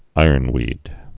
(īərn-wēd)